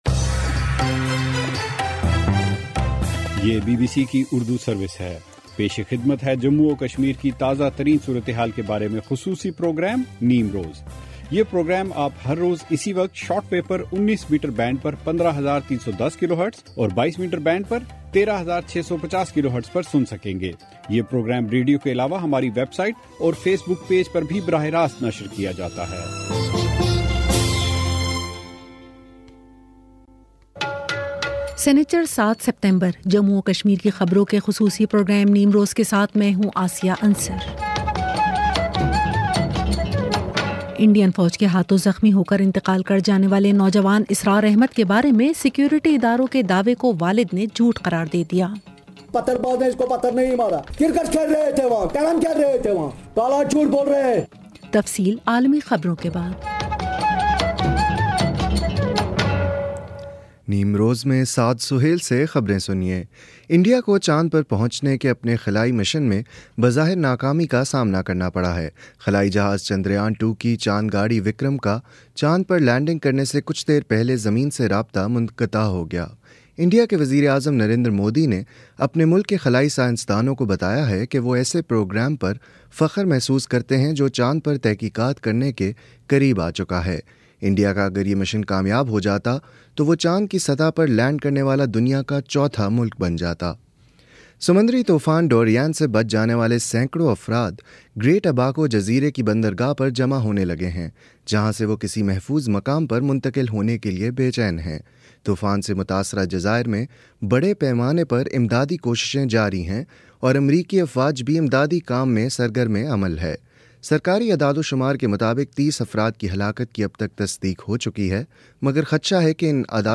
بی بی سی اردو سروس سے جموں اور کشمیر کی خبروں کا خصوصی پروگرام نیم روز